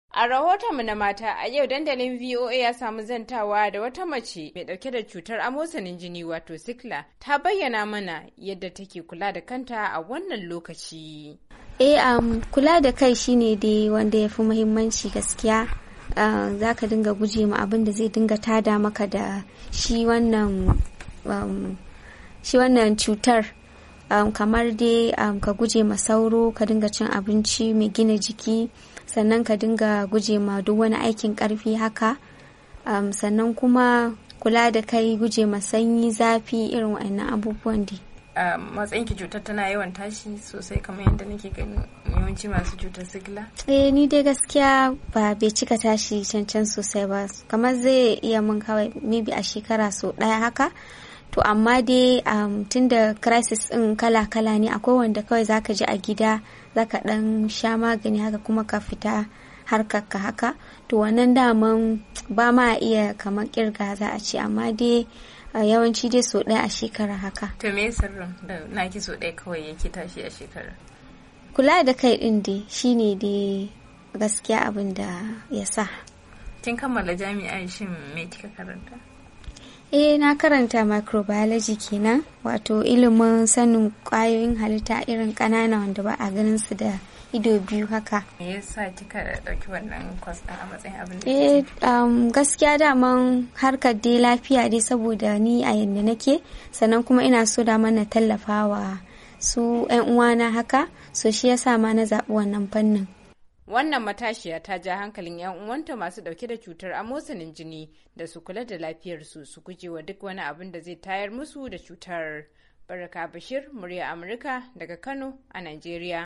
Ta bakin wata baiwar Allah, wadda take fama da cetar Amosalin Jini wanda ake kira a turance Sickle.